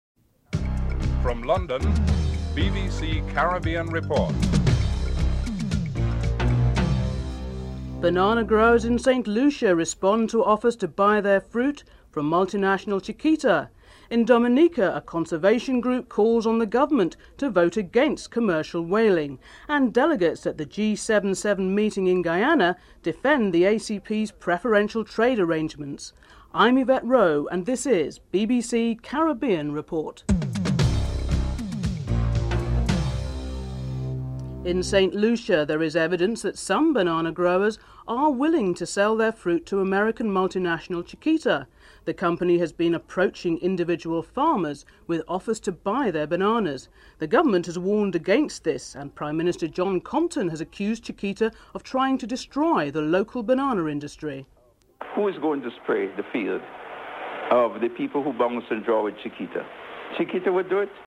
4. In Dominica a conservationist group calls on the government to vote against commercial whaling. Trade and Tourism Minister Norris Provost is interviewed (07:52-10:47)